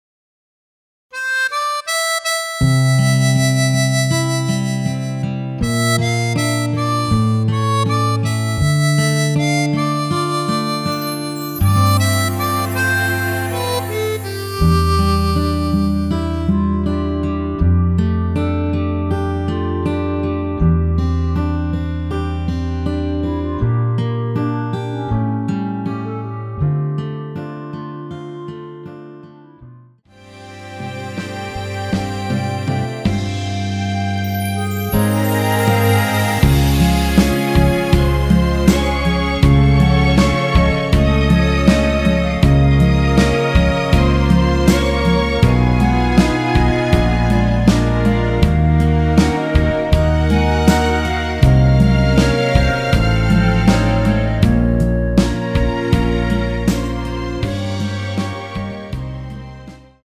원키에서(-1)내린 멜로디 포함된 MR입니다.(미리듣기 확인)
앞부분30초, 뒷부분30초씩 편집해서 올려 드리고 있습니다.
(멜로디 MR)은 가이드 멜로디가 포함된 MR 입니다.